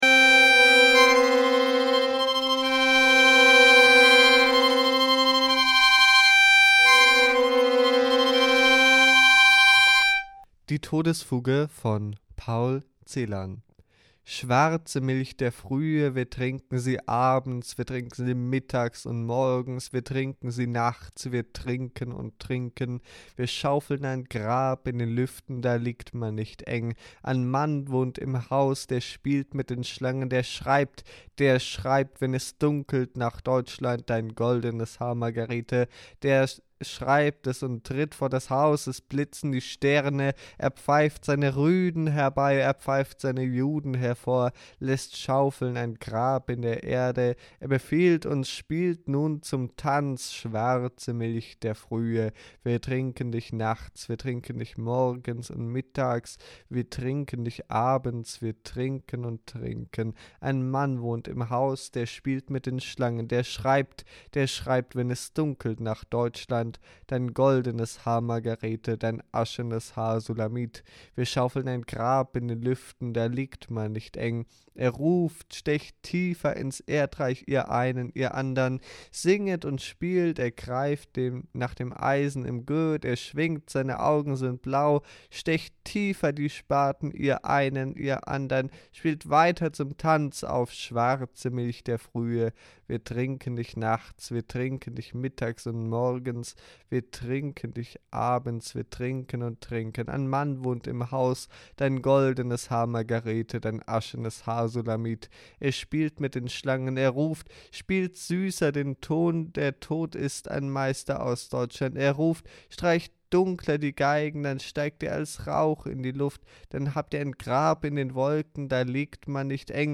Pressekonferenz zum Projekt Sperrstunde
Am Mittwoch den 14.01.2026 gab es in den Räumlichkeiten von Radio free FM eine Pressekonferez.